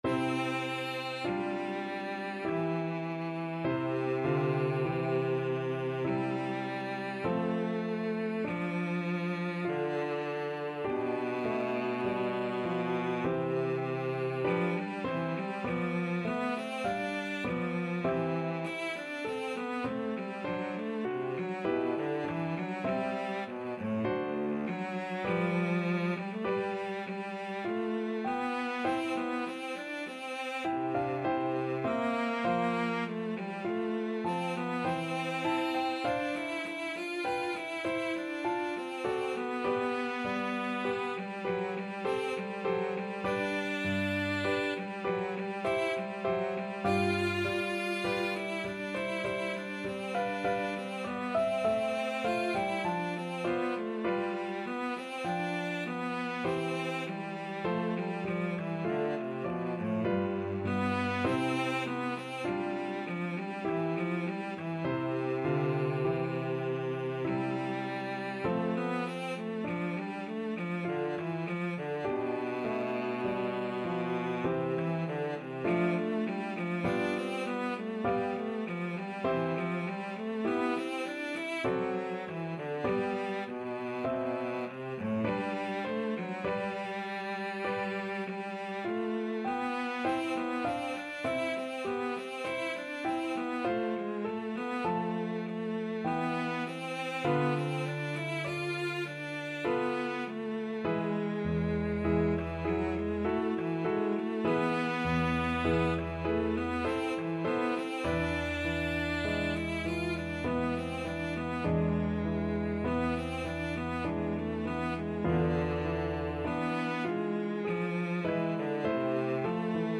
~ = 50 I: Adagio
3/4 (View more 3/4 Music)
Classical (View more Classical Cello Music)